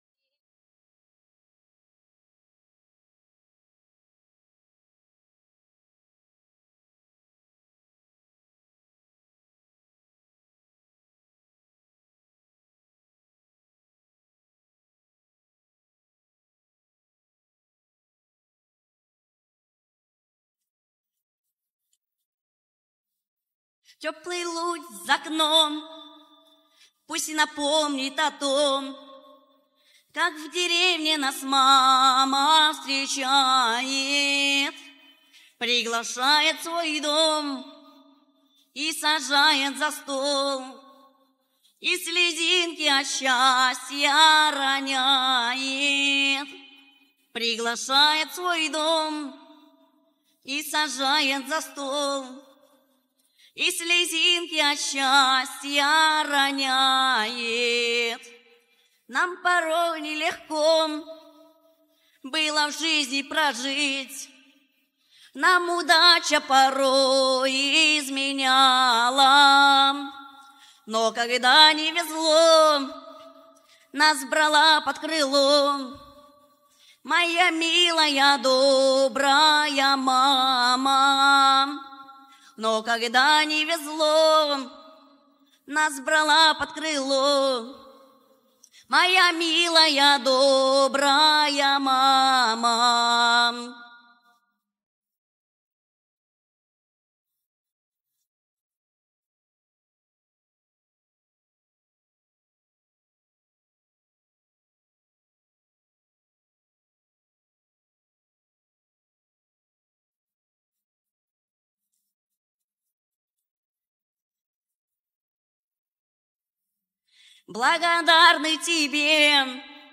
• Категория: Детские песни
теги: мама, деревня, гармонь, народный мотив, минус